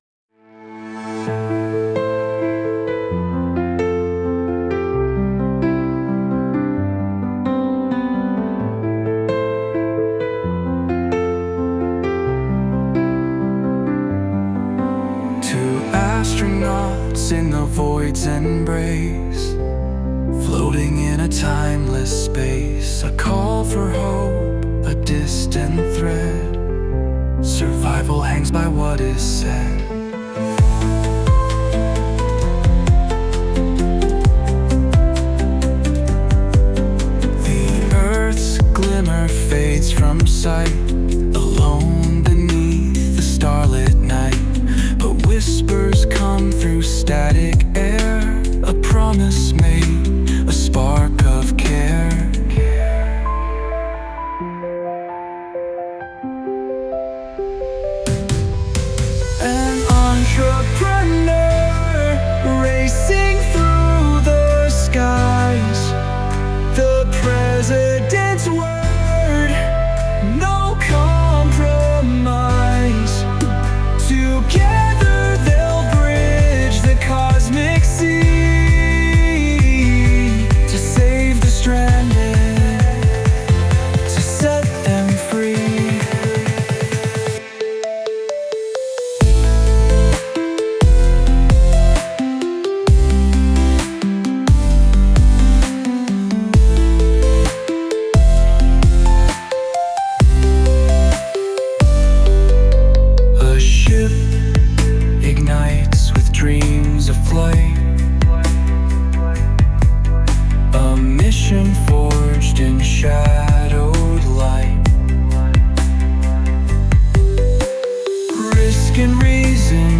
Lost Among Stars, opera propria, generata con AI